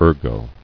[er·go]